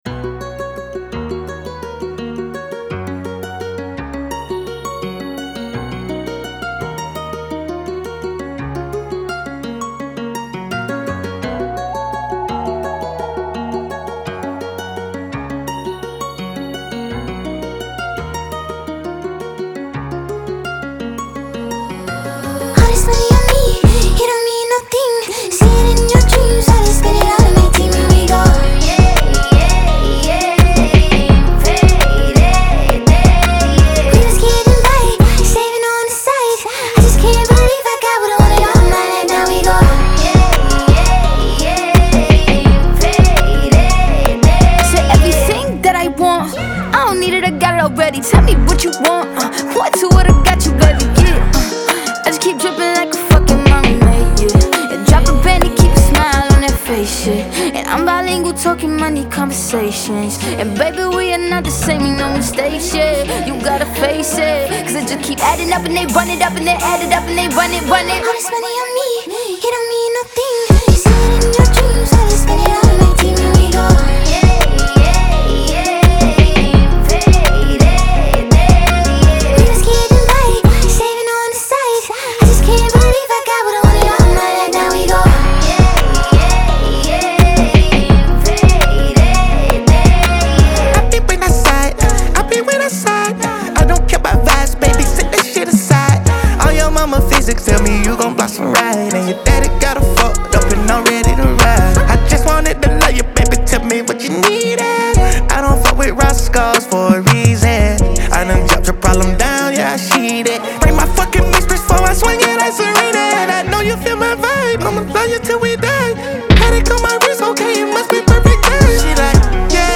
Genre : Pop, R&B